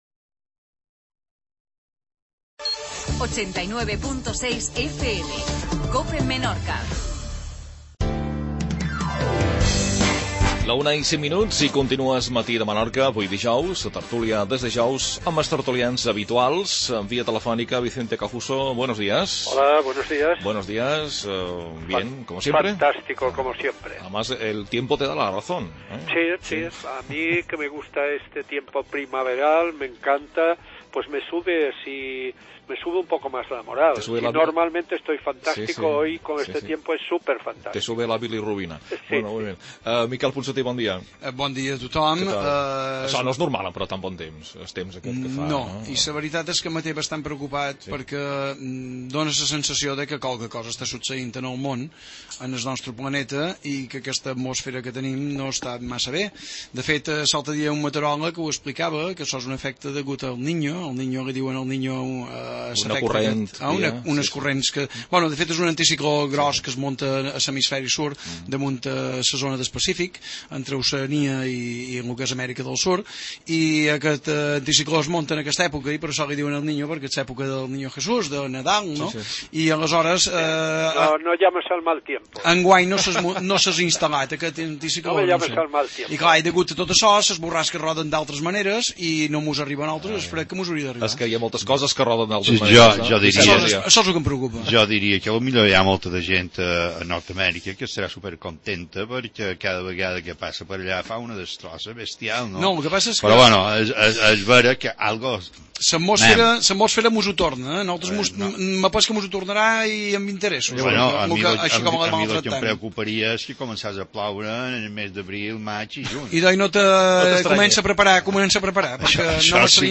Tertulia.